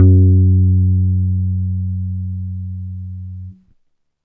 10-F#.wav